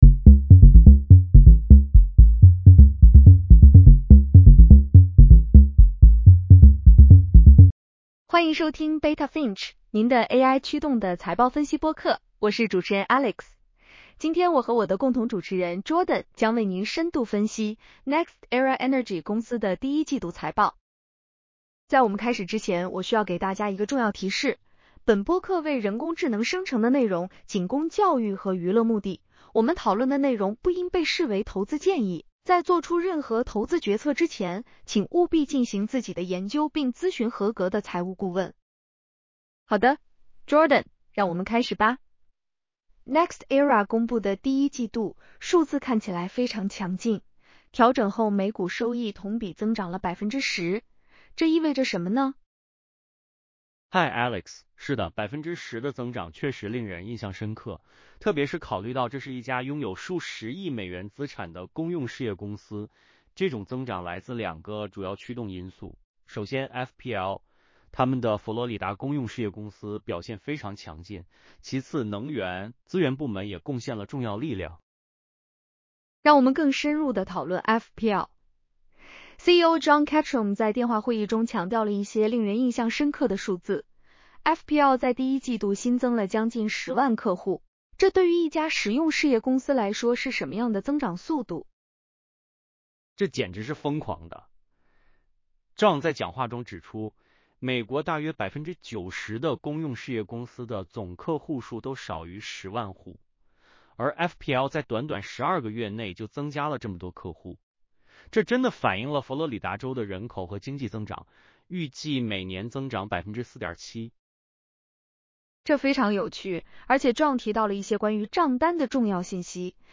NextEra Energy Q1 2026 earnings call breakdown. Full transcript & podcast. 10 min. 5 languages.